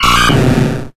de1580c54c313104ceccb036e87a03d6f4553b88 infinitefusion-e18 / Audio / SE / Cries / TOTODILE.ogg infinitefusion d3662c3f10 update to latest 6.0 release 2023-11-12 21:45:07 -05:00 11 KiB Raw History